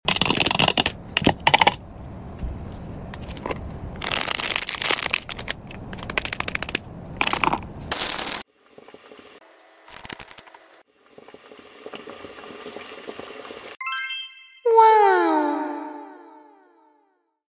Wax Melting ASMR You Didn’t Sound Effects Free Download